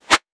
weapon_bullet_flyby_24.wav